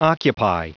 Prononciation du mot occupy en anglais (fichier audio)
Prononciation du mot : occupy